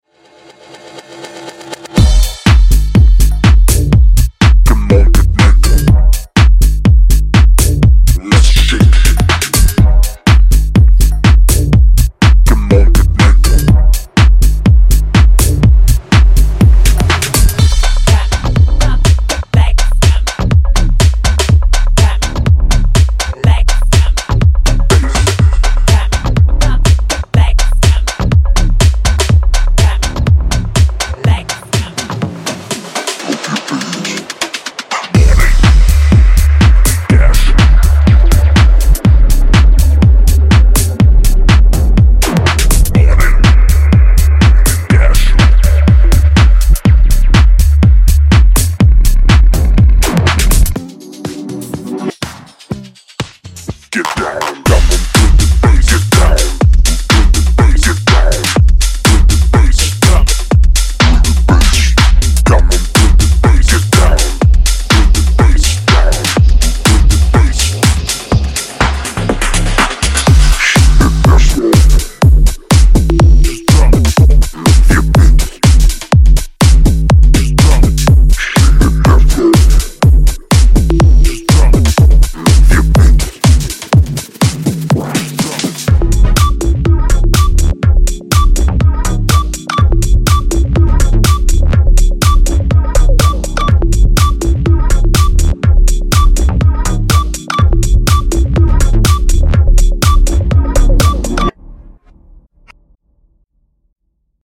3. House
该包由Phat G-House Bass，抽象合成器和和弦，完整鼓和顶级循环循环，面向体裁的鼓声和人声过量使用！
查看此Groovy，Dark and Twisted演示轨道